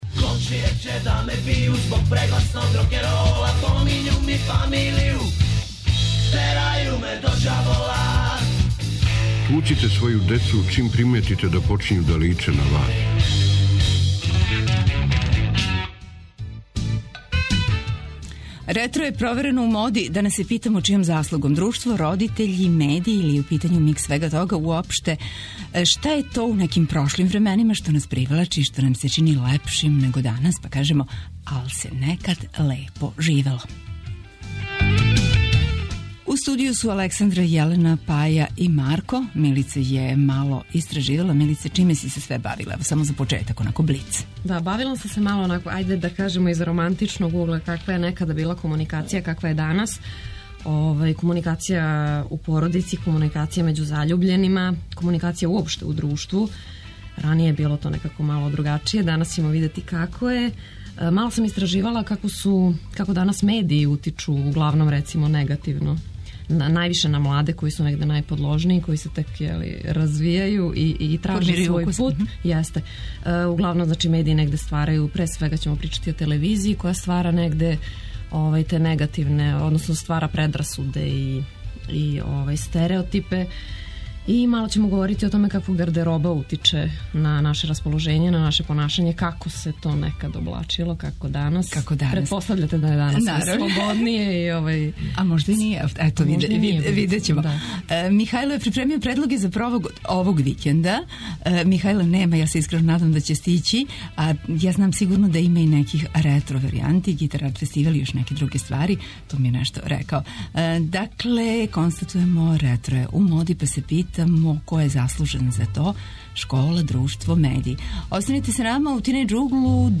Шта је то у прошлим временима што нам се чини бољим него данас? Гости су средњошколци и студенти.